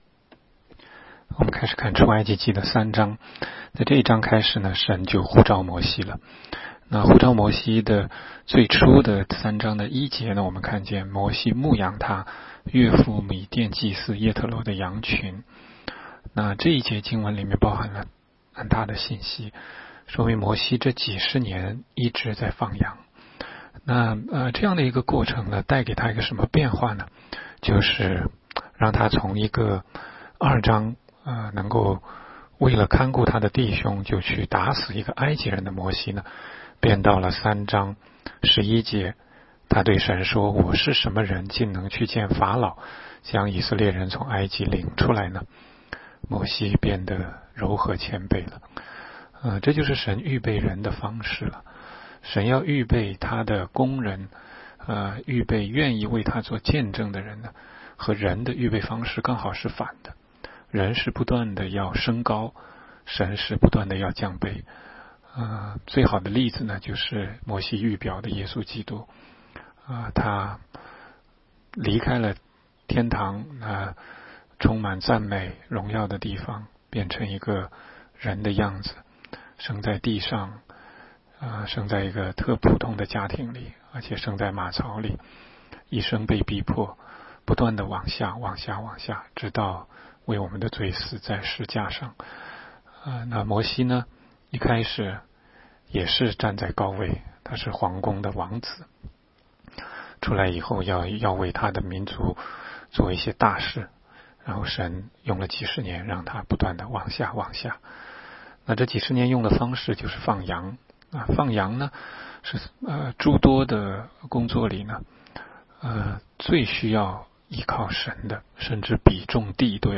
16街讲道录音 - 每日读经-《出埃及记》3章